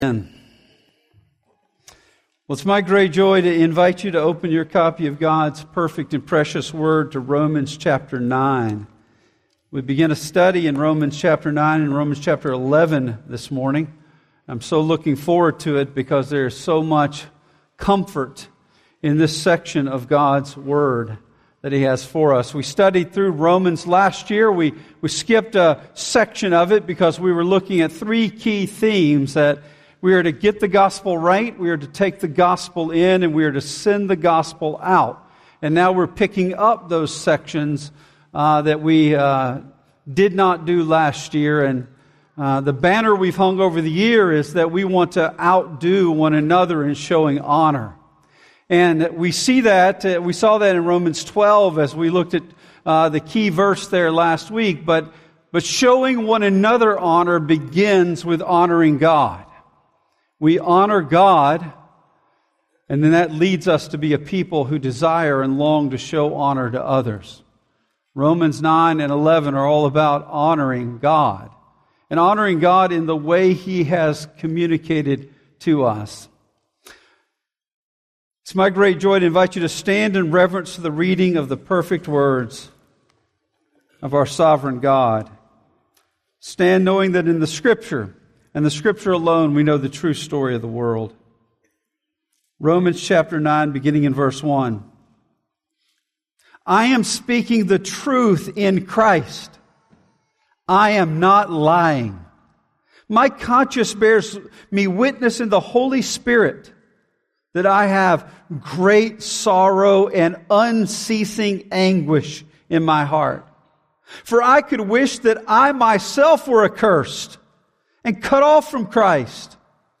In our first sermon in our "Honoring God" series we see the ramifications of God's word never failing.